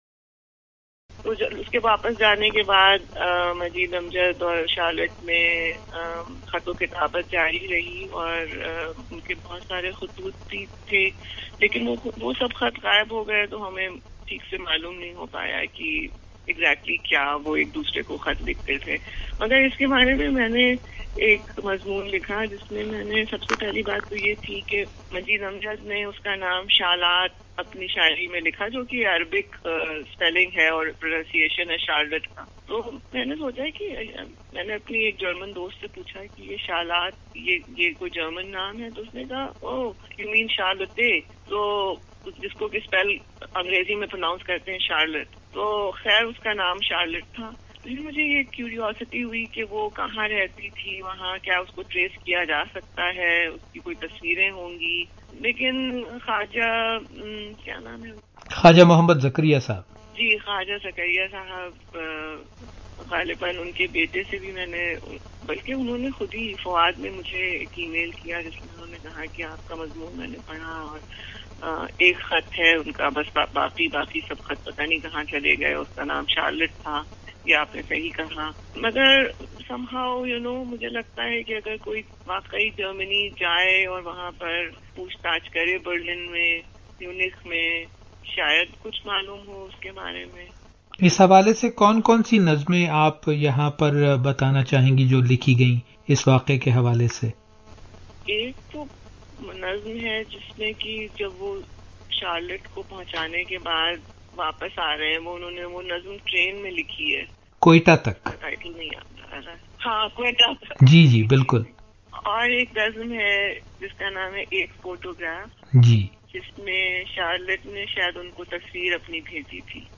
via telephone (my apologies for distracting telephone noises